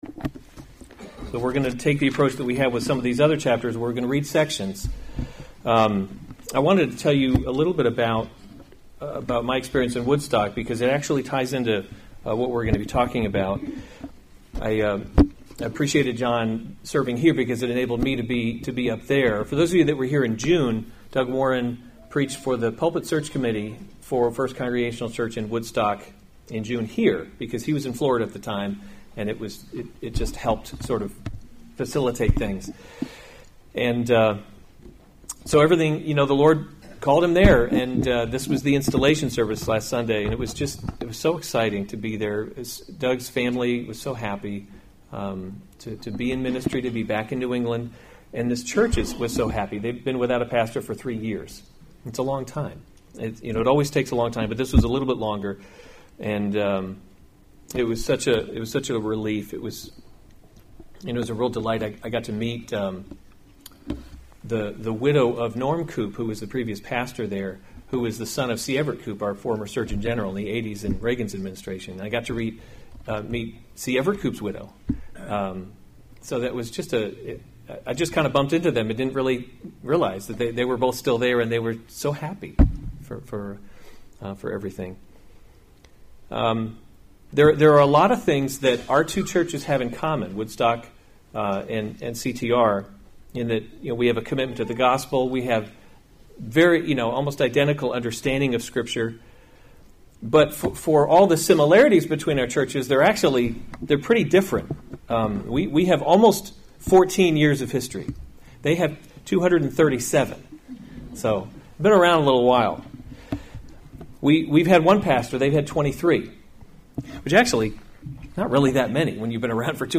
October 27, 2018 1 Kings – Leadership in a Broken World series Weekly Sunday Service Save/Download this sermon 1 Kings 7 Other sermons from 1 Kings Solomon Builds His Palace […]